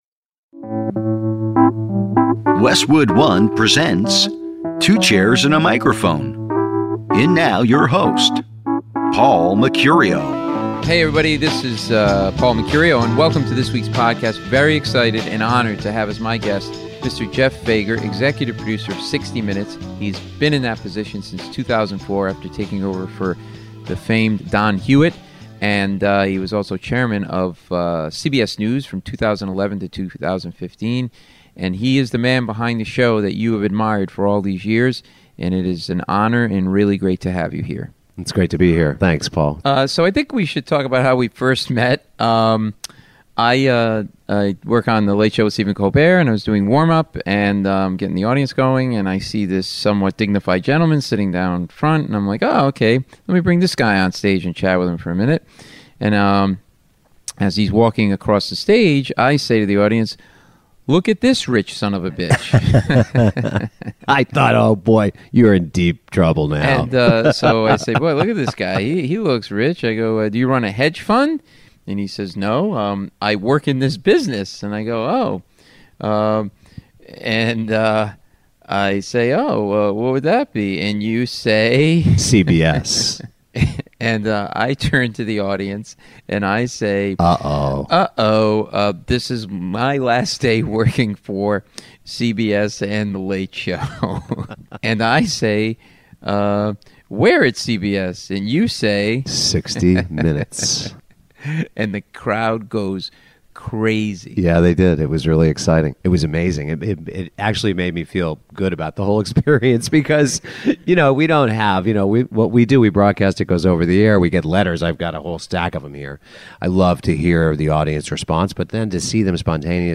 A rare insider's conversation with this multiple Emmy & Peabody Award winner who is and has been at the pinnacle of broadcast journalism in America for over 30 years. Jeff's only the 2nd Executive Producer in the 50 year history of "60 Minutes."